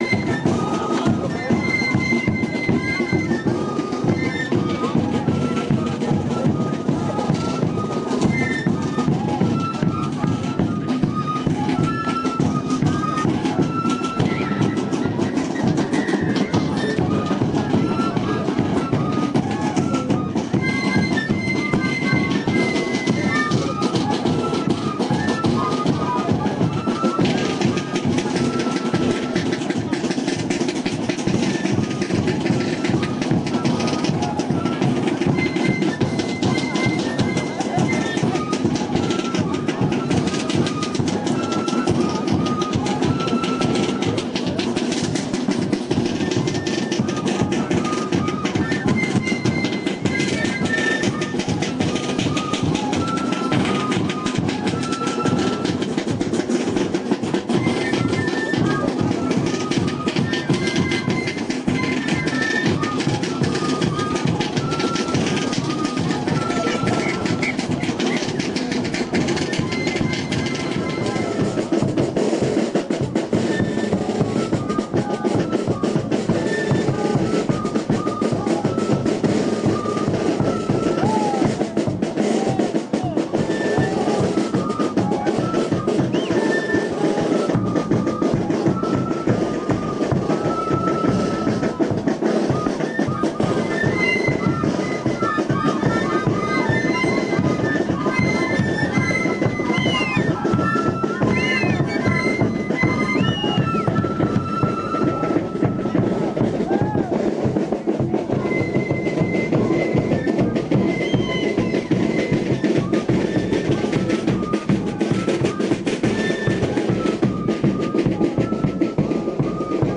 Versión de pinkillu tocado en diferentes ayllus de la provincia de Puno durante la temporada de carnavales.